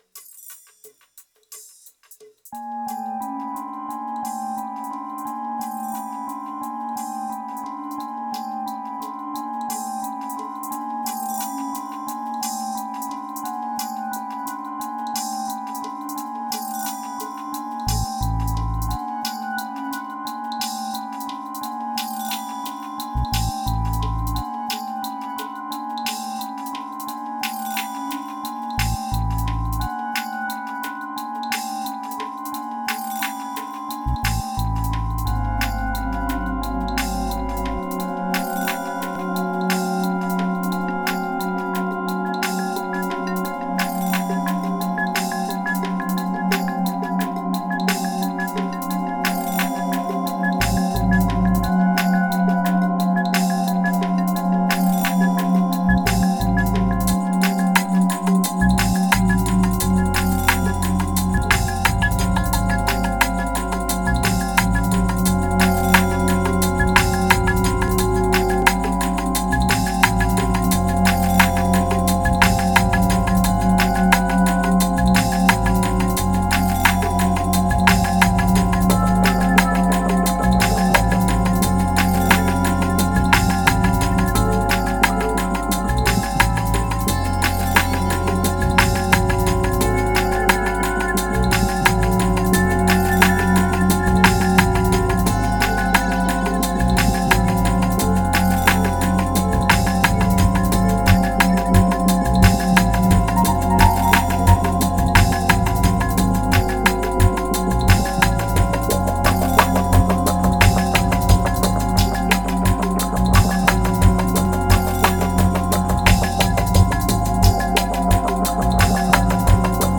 2140📈 - 32%🤔 - 88BPM🔊 - 2015-02-14📅 - -24🌟